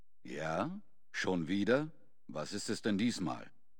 Fallout: Brotherhood of Steel: Audiodialoge
FOBOS-Dialog-Richard-004.ogg